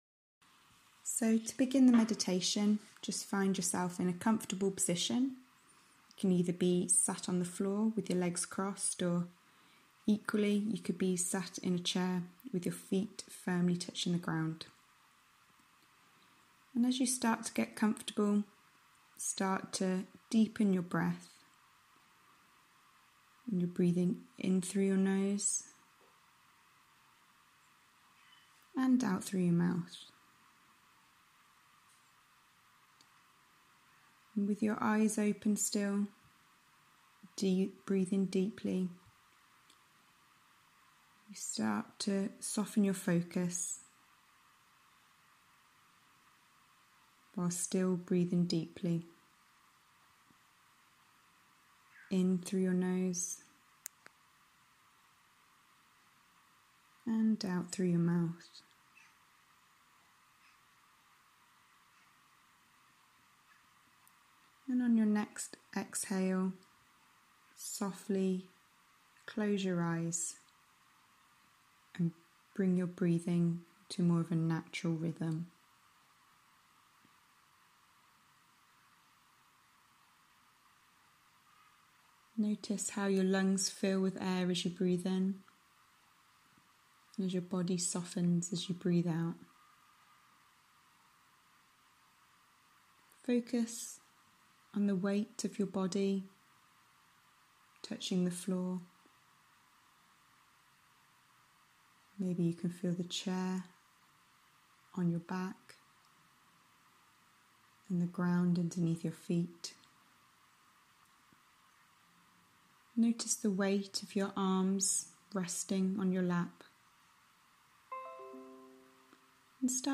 meditationvoiceover.mp3